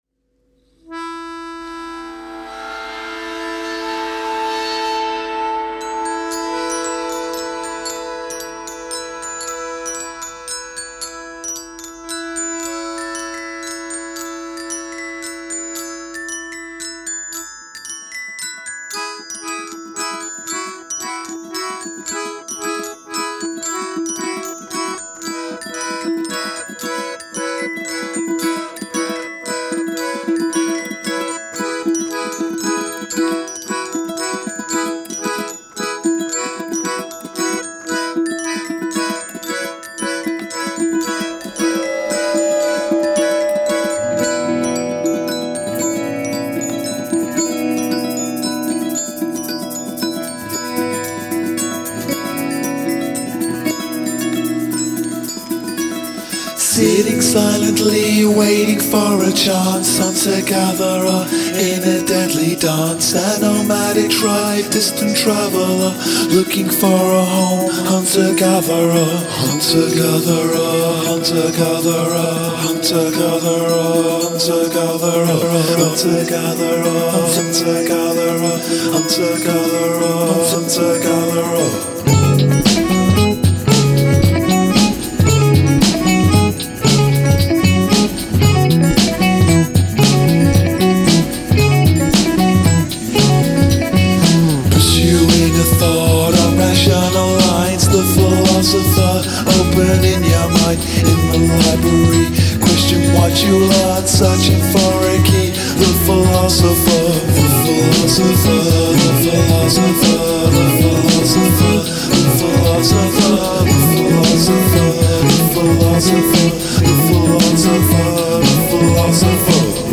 Prominent musical repetition